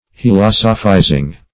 hilosophizing (f[i^]*l[o^]s"[-o]*f[imac]`z[i^]ng).]
hilosophizing.mp3